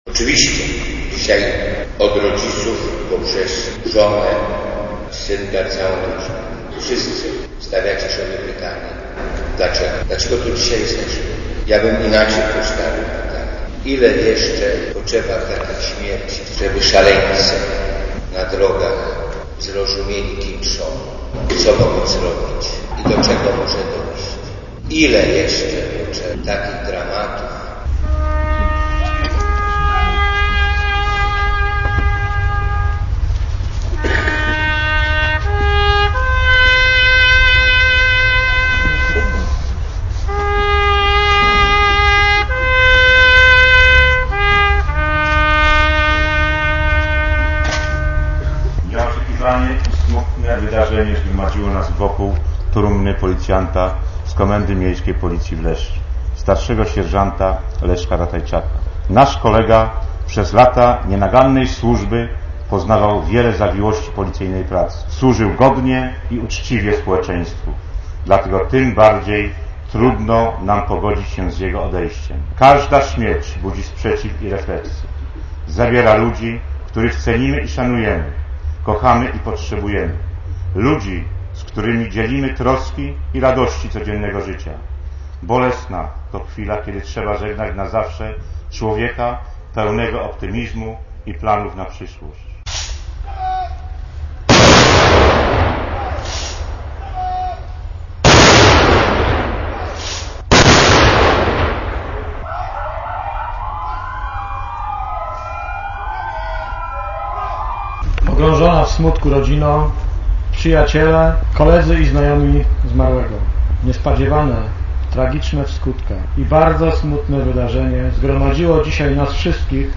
Msza odbyła się w kościele świętego Antoniego, a udział w niej wzięli obok rodziny także policjanci ze wszystkich komend w regonie.
Policjanta żegnała też dziś salwa honorowa, policyjne syreny.